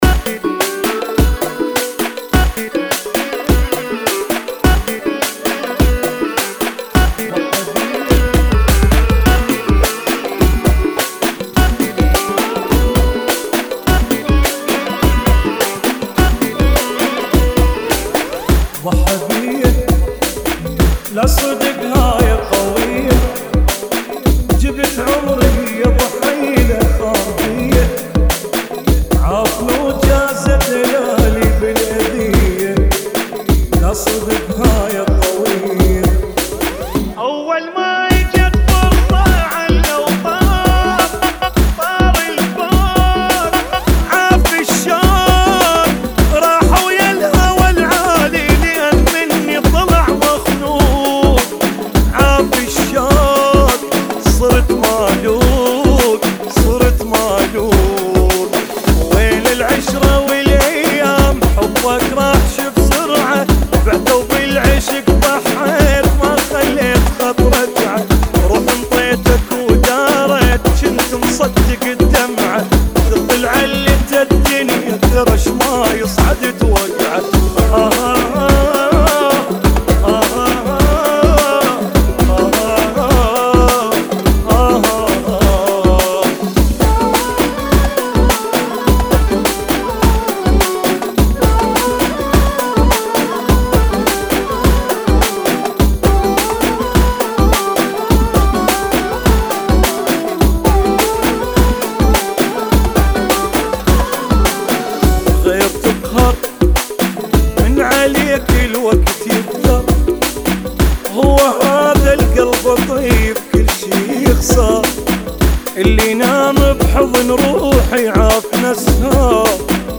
[ 104 Bpm ]